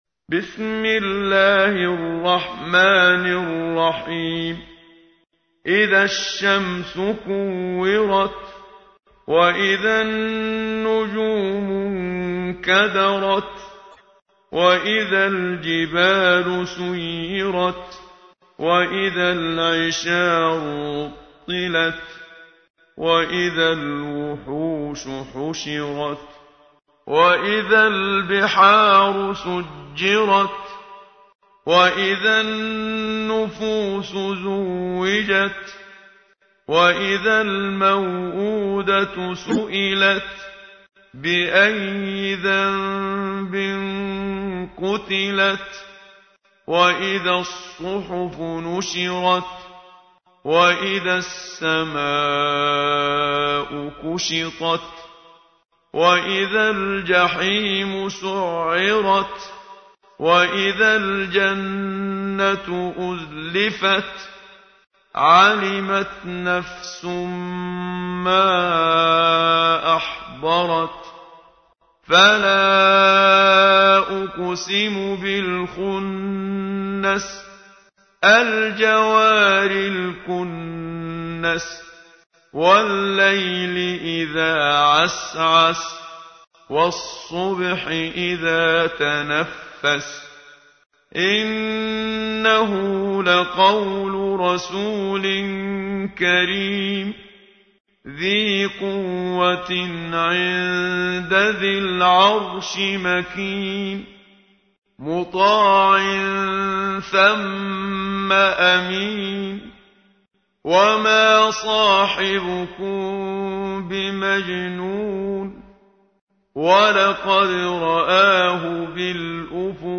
تحميل : 81. سورة التكوير / القارئ محمد صديق المنشاوي / القرآن الكريم / موقع يا حسين